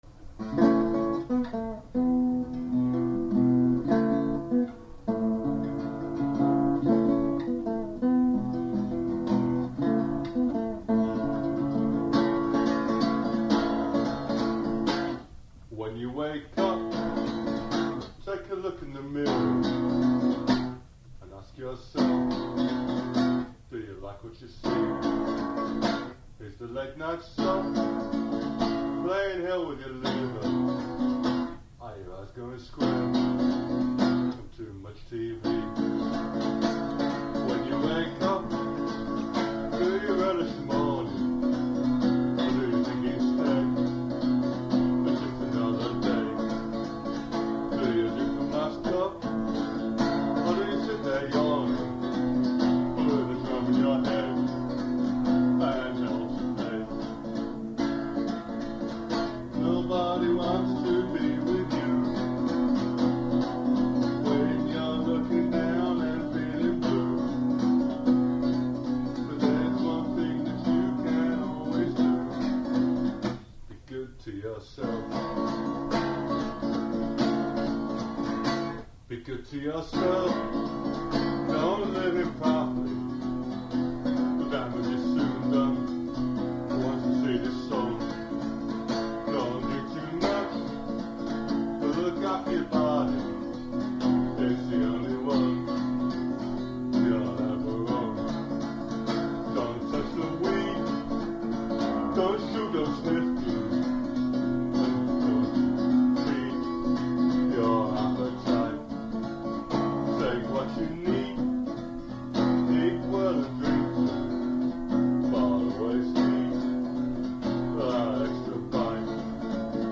here to play the original demo.